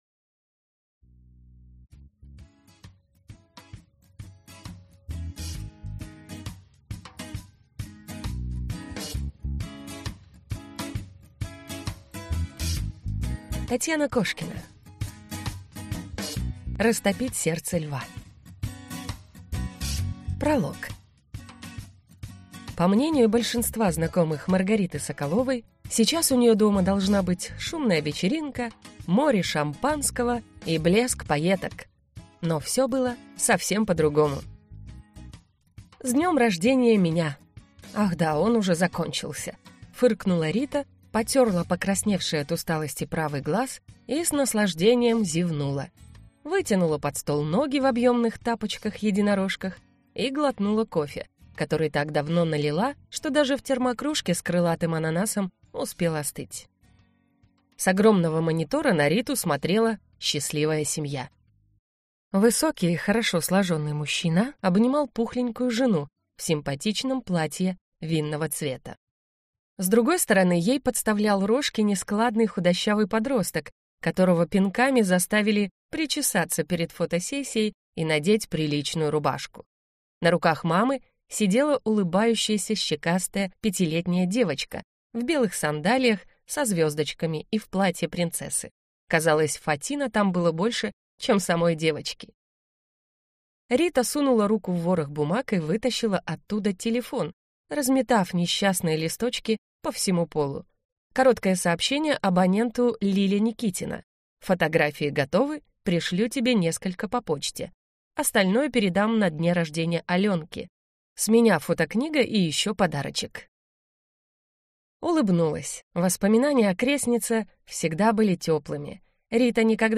Аудиокнига Растопить сердце Льва | Библиотека аудиокниг